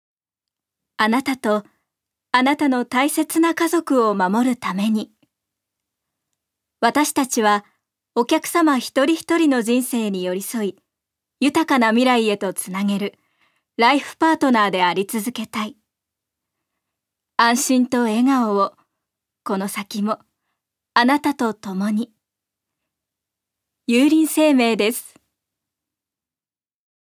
女性タレント
ナレーション２